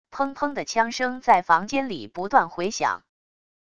砰砰的枪声在房间里不断回响wav音频